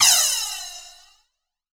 Crash OS 03.wav